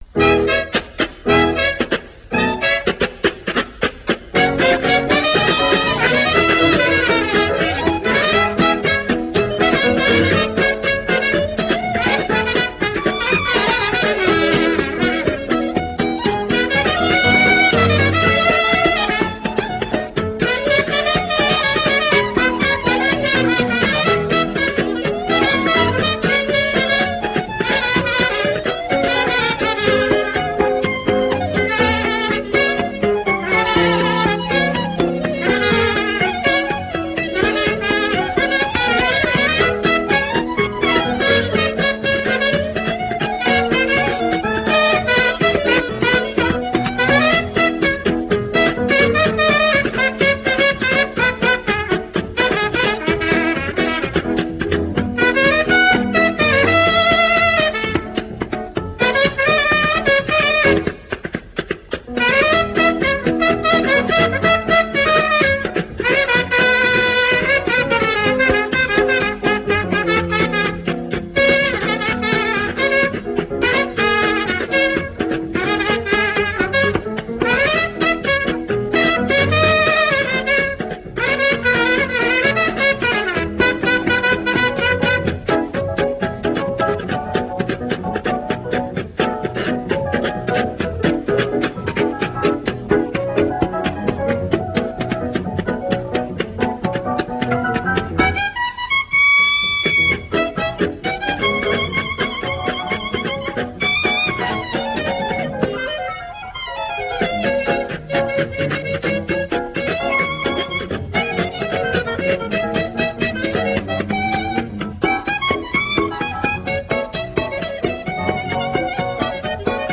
out-of-copyright early blues, jazz and folk recordings